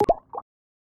Ice cube Bubble Notification 2.wav